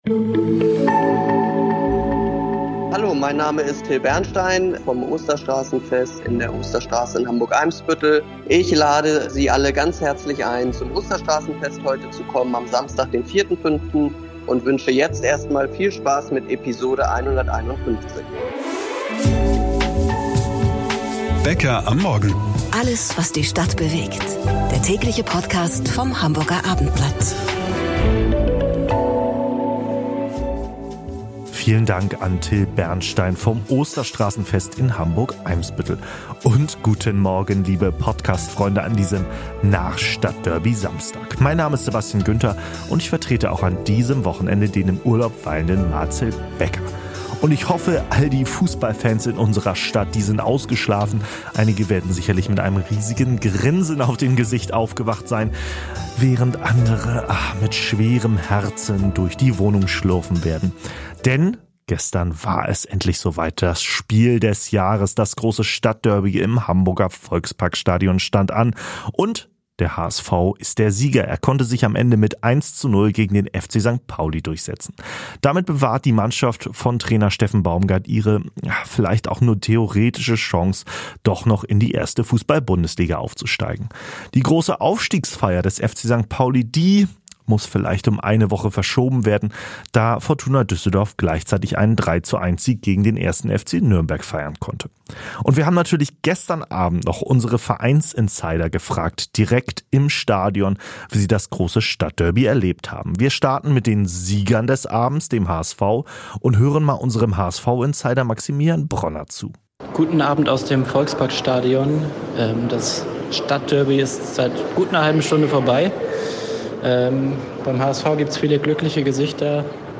Exklusive Informationen nach dem Stadtderby direkt aus dem Volksparkstadion von unseren Sport-Insidern
im Interview zum Ukraine-Krieg